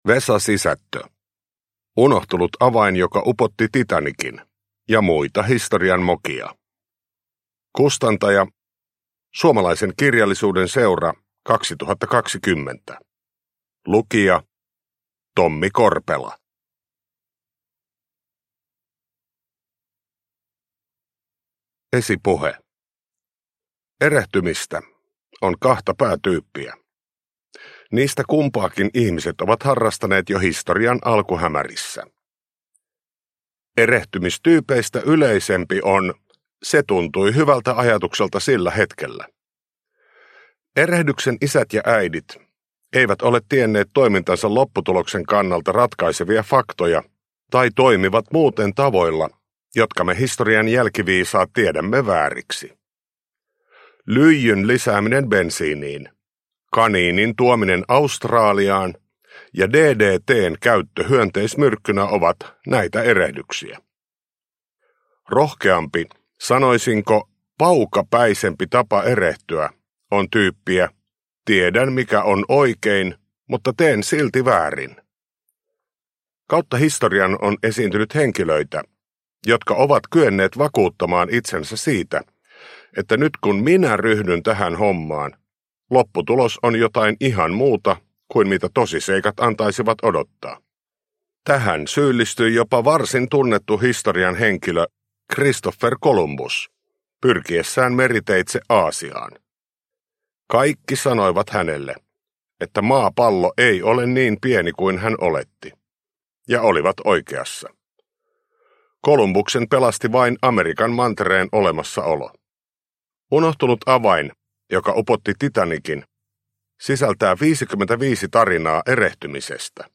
Uppläsare: Tommi Korpela